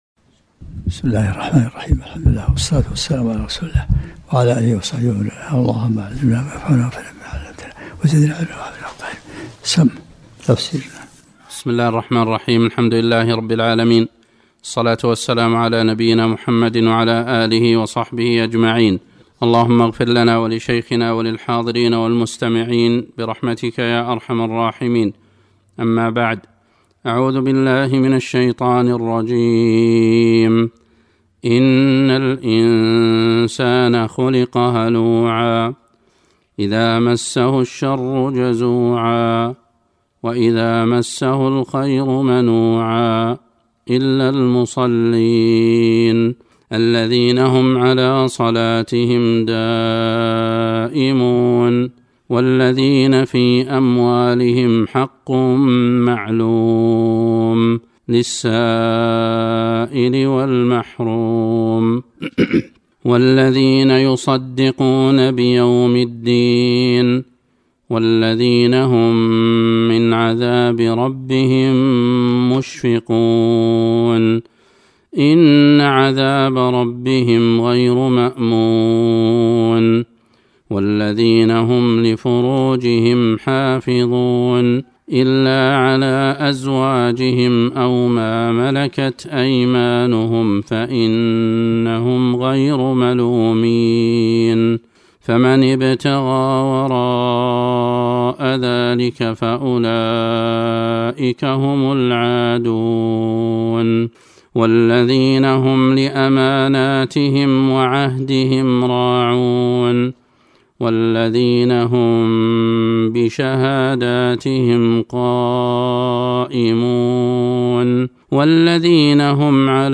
الدروس العلمية